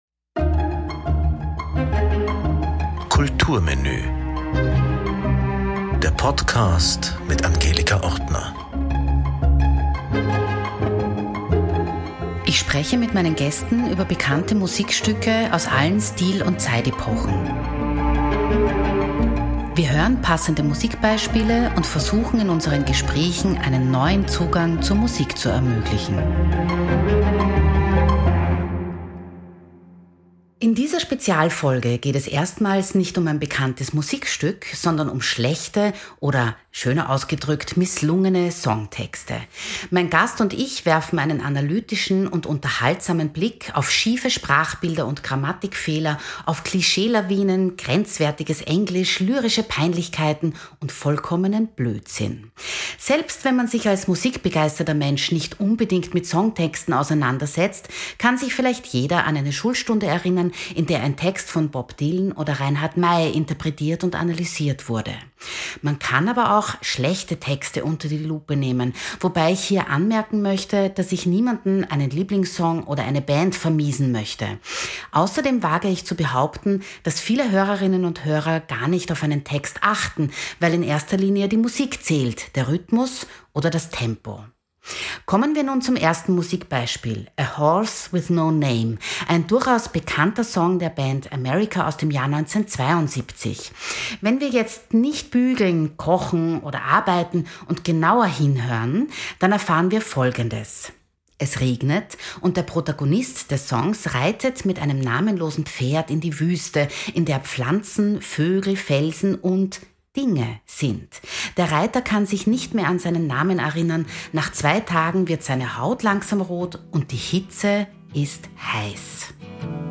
Im Gespräch
Umrahmt wird unser Gespräch von unterschiedlichen Musikeinspielungen, die uns zum Nachdenken und zum Lachen bringen…